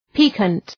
piquant.mp3